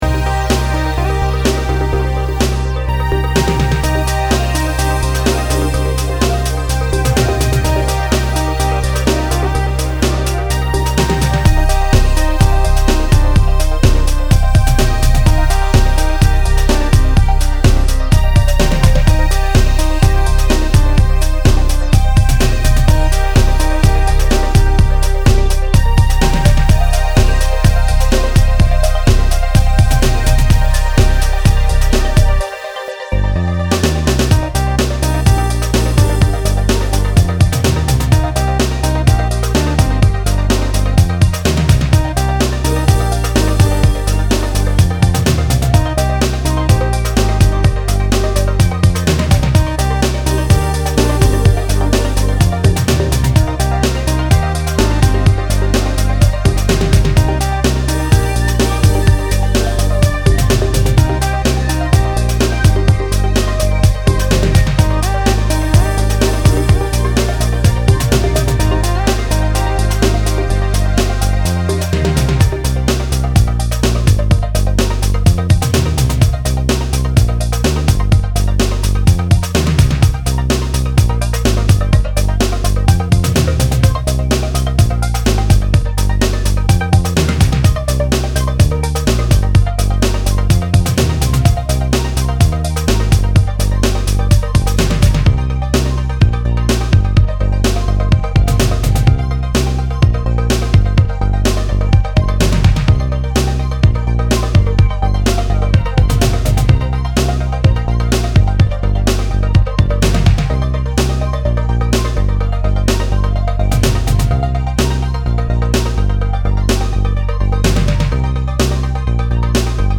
降り注ぐオプティミスティックなシンセがセットのクライマックスに最適なブレイクビーツ/エレクトロ